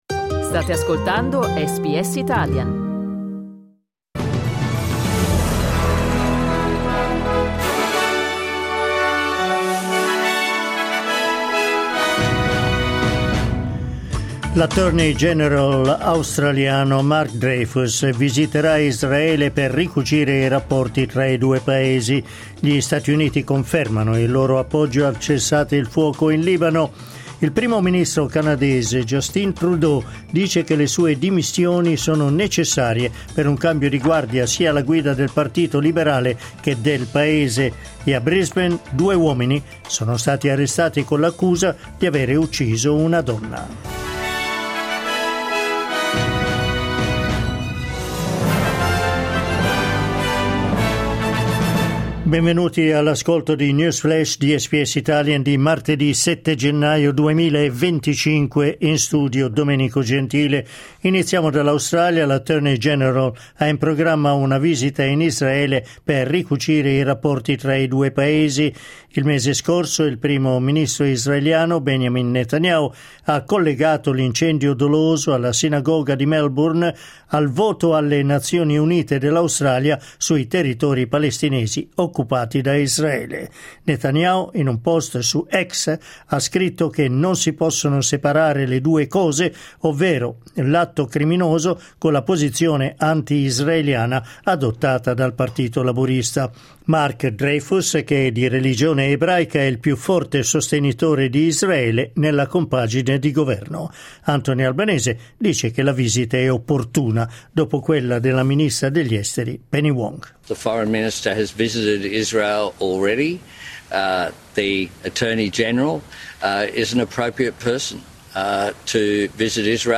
News flash martedì 7 gennaio 2025
L’aggiornamento delle notizie di SBS Italian.